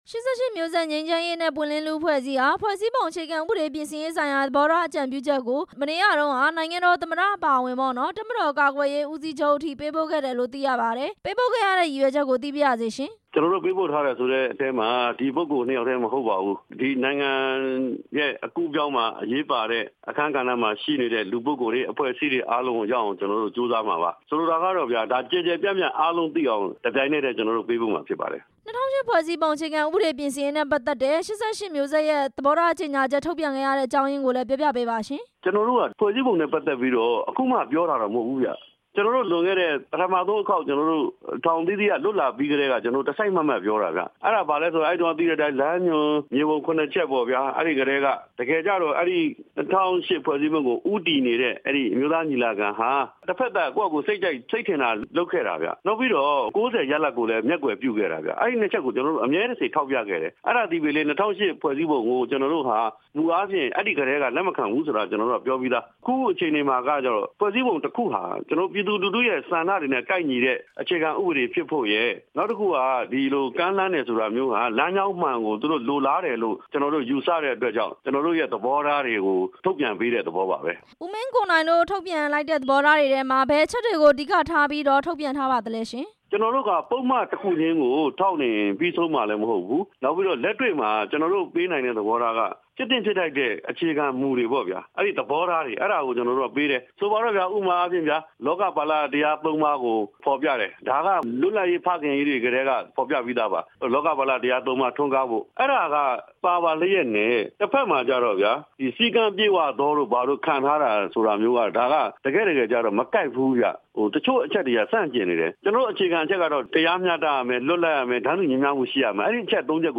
ကျောင်းသားခေါင်းဆောင် ကိုမင်းကိုနိုင်နဲ့ မေးမြန်းချက်